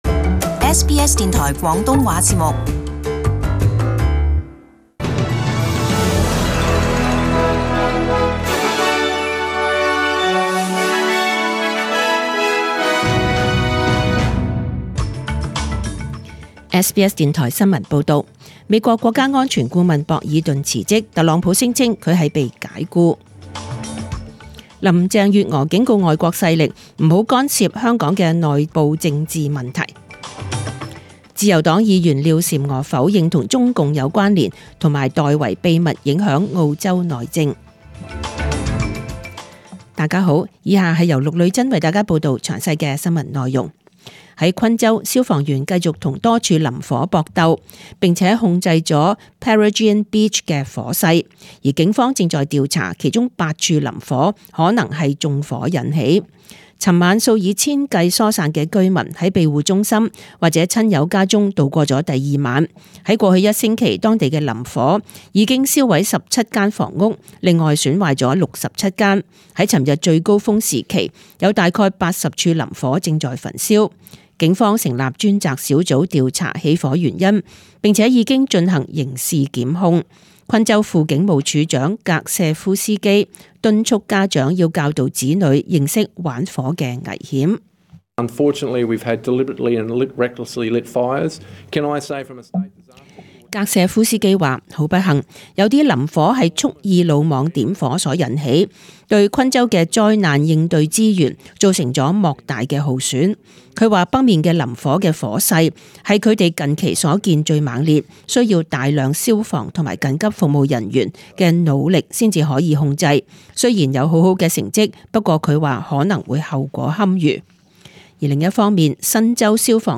請收聽本台為大家準備的詳盡早晨新聞